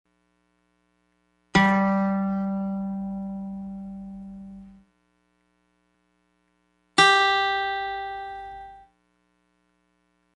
A top tone and a bottom tone, so to speak.
Nature's Interval - Guitar
guitar_octave.mp3